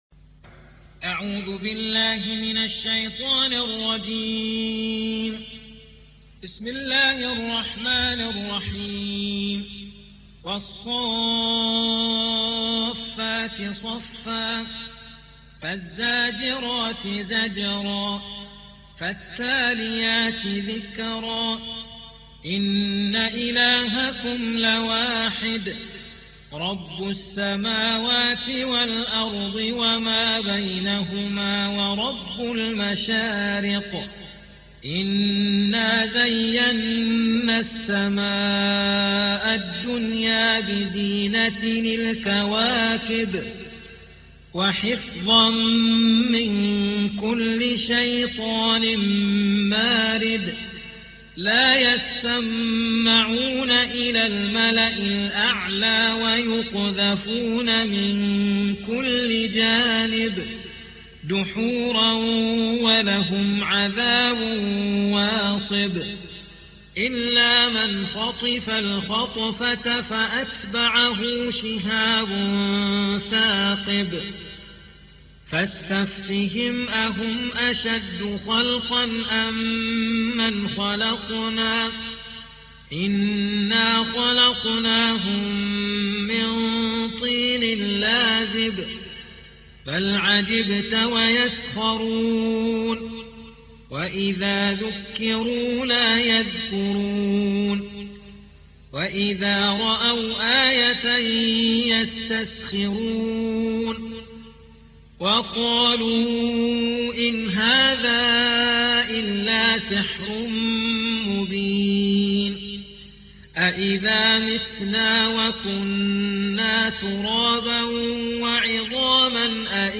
37. سورة الصافات / القارئ